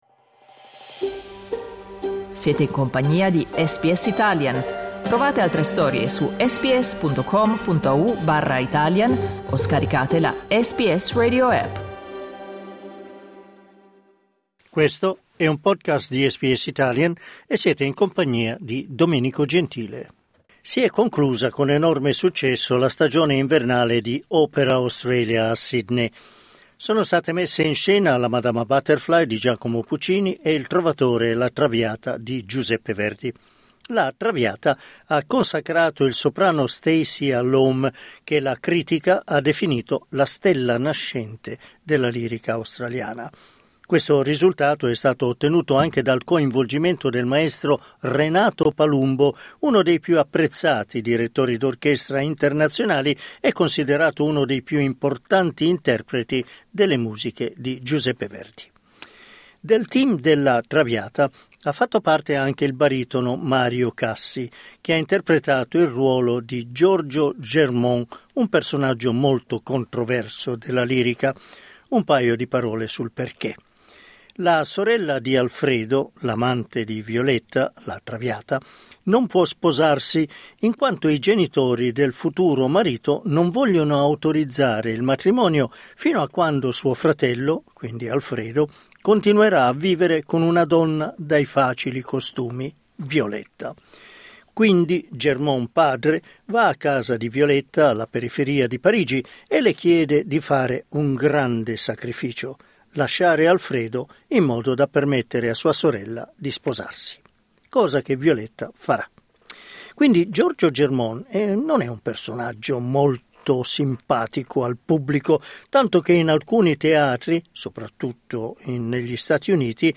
In questa intervista